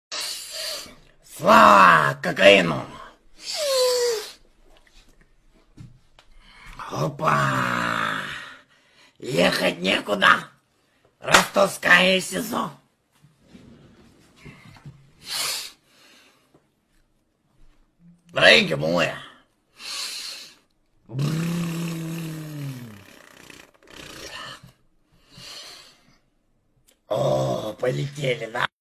Übrigens, für diejenigen, welche nun schon wieder überbeissen - es handelt sich hier um einen Deepfake - welcher aber tatsächlich auch direkt aus dem Twitterkanal vom kolumbianischen Wintersportfreund ...